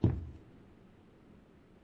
FootstepHandlerMarble1.wav